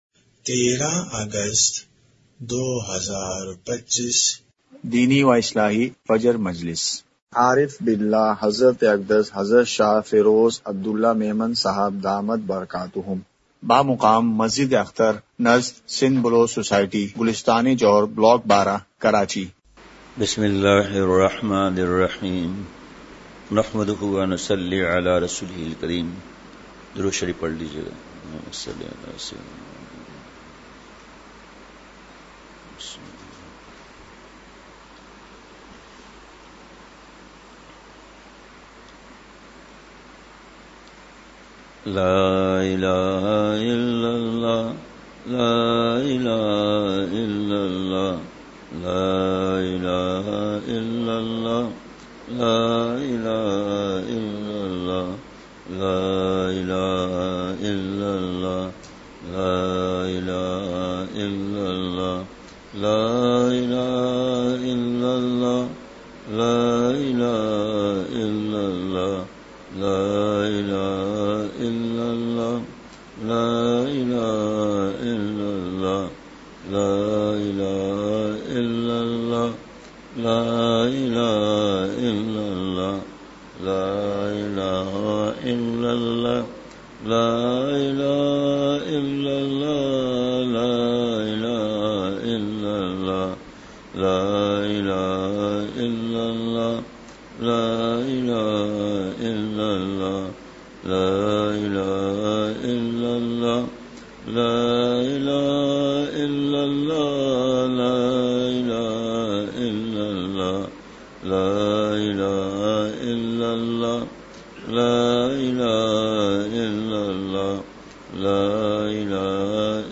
مقام:مسجد اختر نزد سندھ بلوچ سوسائٹی گلستانِ جوہر کراچی
مجلس ذکر